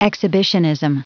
Prononciation du mot exhibitionism en anglais (fichier audio)
Prononciation du mot : exhibitionism